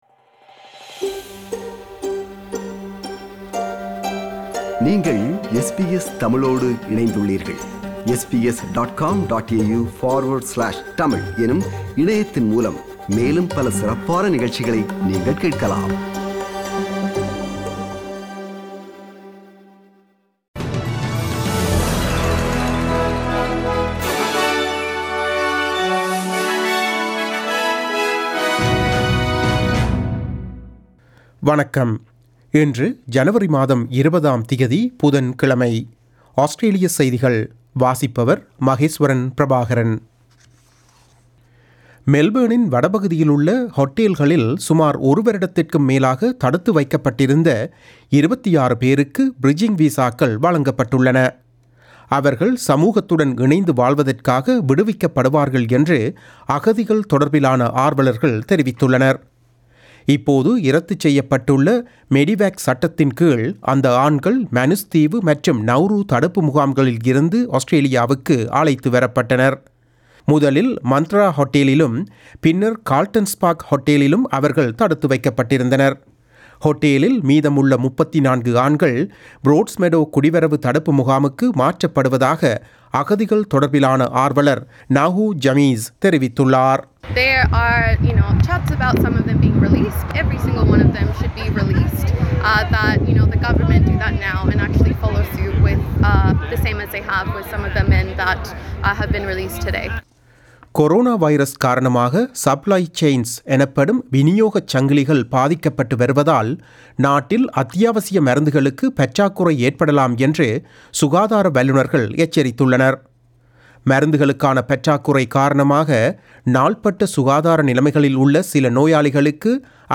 Australian news bulletin for Wednesday 20 January 2021.